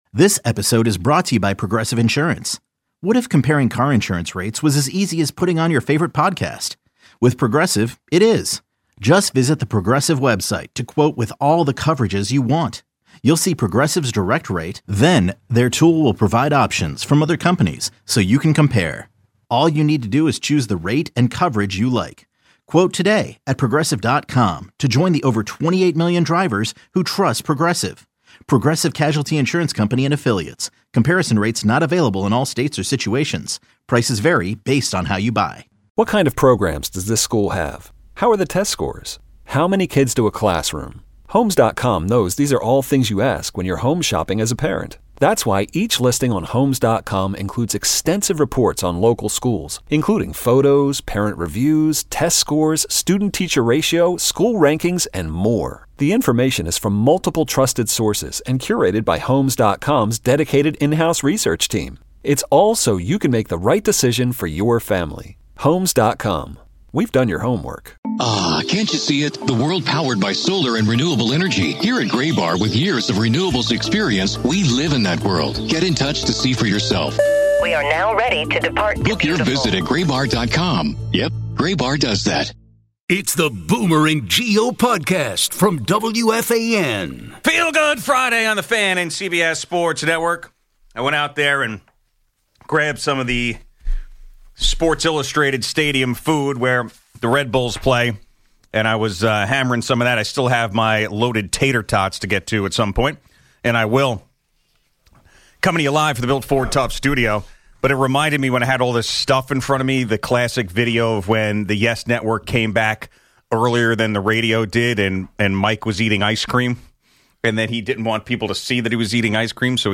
Callers share their road rage experiences.